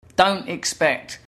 Here then are some examples of don’t pronounced clearly in natural native speech. Note that it’s very common for the /t/ to be pronounced as a glottal stop – a silent gap with the breath briefly stopped in the throat – but this is not the same thing as omitting the /t/ altogether.